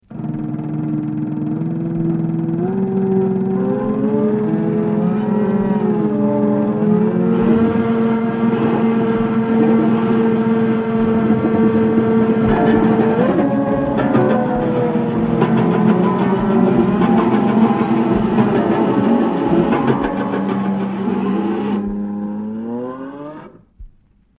Risvoglio“ Omgevingsgeluiden spelen een belangrijke rol